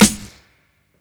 Snares
SFGH_SNR.wav